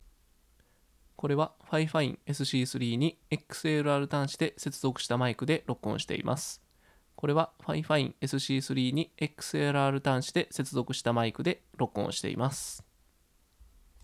音質テスト
こちらがFIFINE AmpliGame SC3にXLR端子で接続したマイクです。
どちらのマイクも1万円クラスのマイクなのでマイク音質は両方良いですが、FIFINE AmpliGame SC3に接続した方がコンプレッサーなどがなく、自然な感じの音に聞こえます。